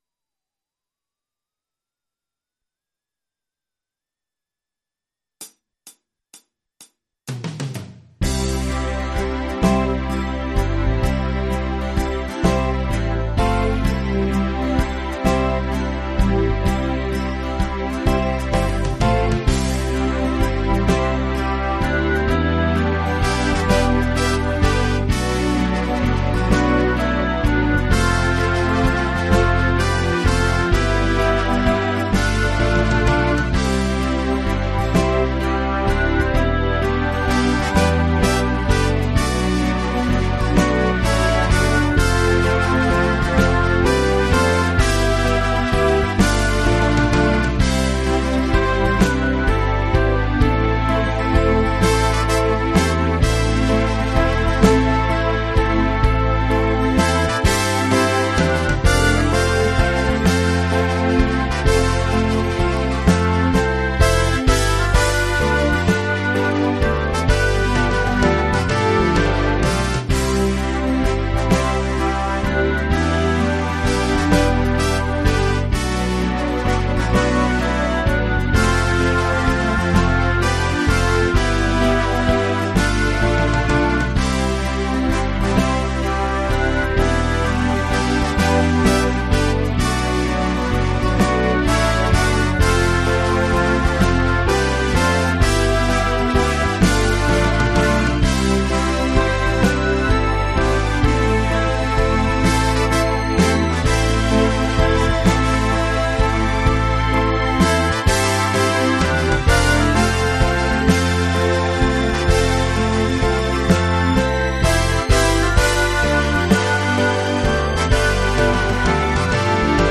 versión instrumental multipista